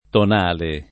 tonale